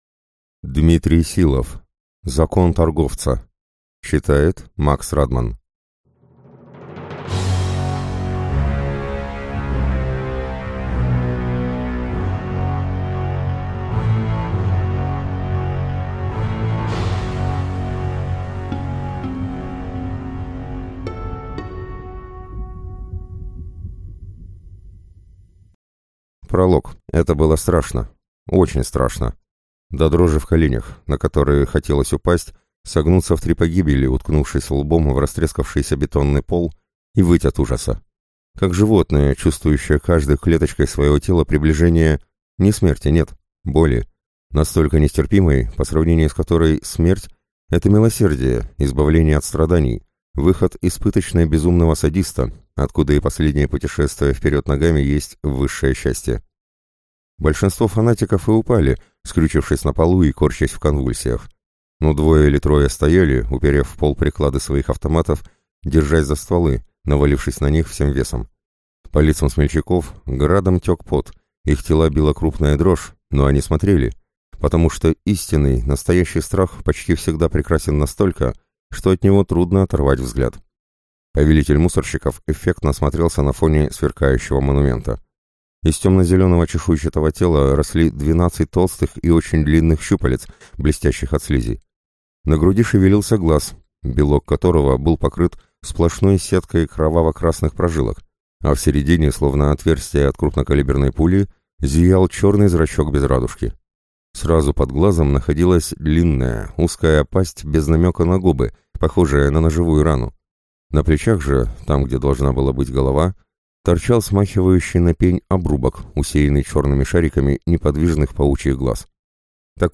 Аудиокнига Закон торговца | Библиотека аудиокниг